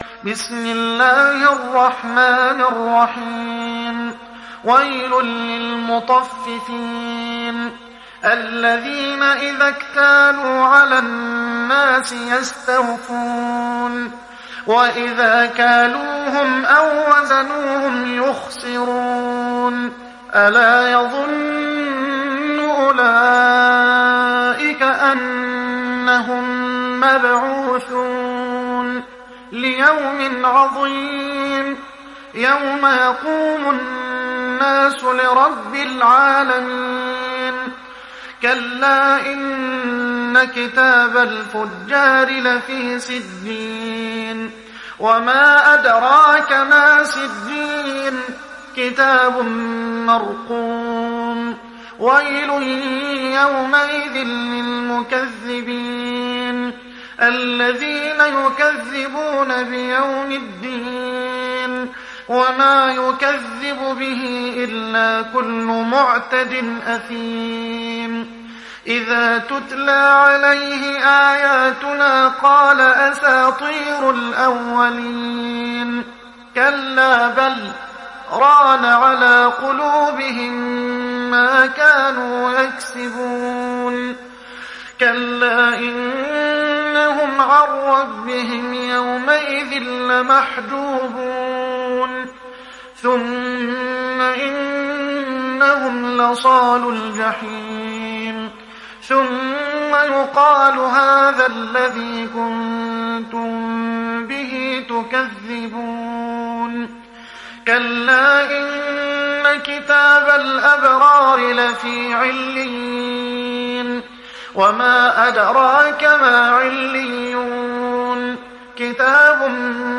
تحميل سورة المطففين mp3 بصوت محمد حسان برواية حفص عن عاصم, تحميل استماع القرآن الكريم على الجوال mp3 كاملا بروابط مباشرة وسريعة